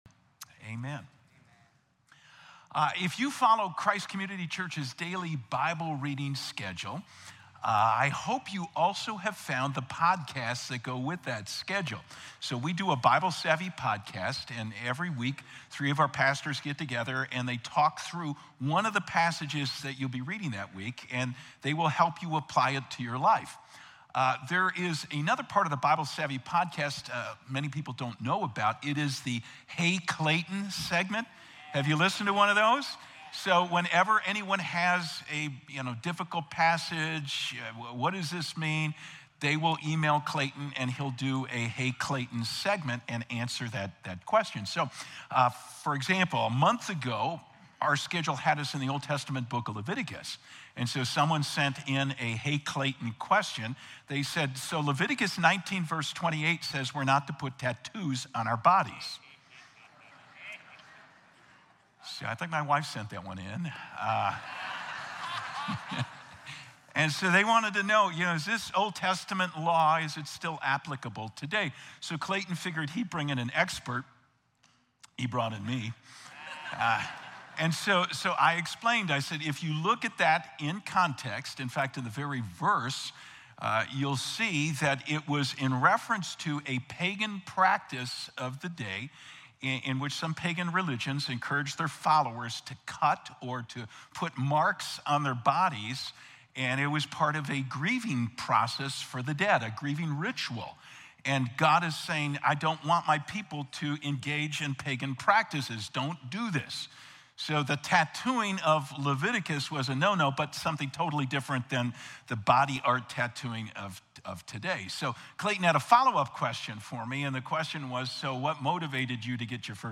4-6-25-Sermon.mp3